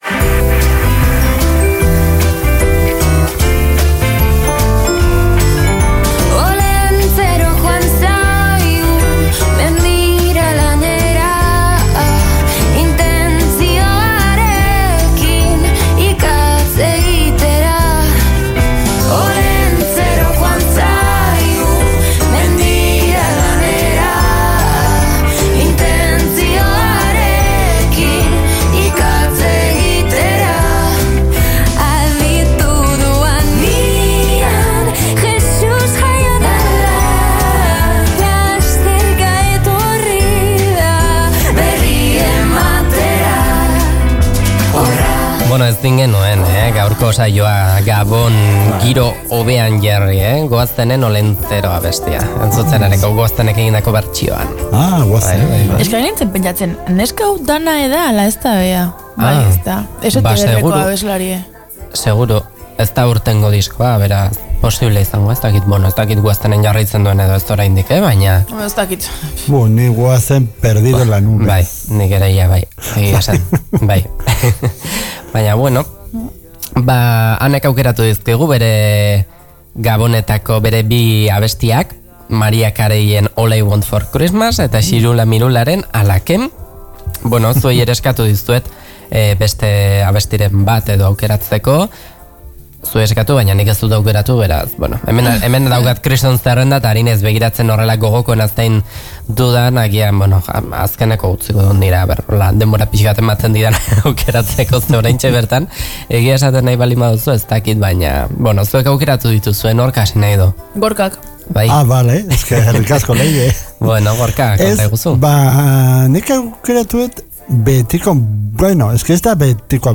Gabonetan ere ez da musikarik falta Ataun Irratiaren uhinetan, ez eta ‘Bide Ertzean’ saioan ere. Hilabete honetako tertulian, egun berezi hauekin lotura izan dezakeen gure abestirik gogokoenak aukeratu eta entzunarazi nahi izan ditugu.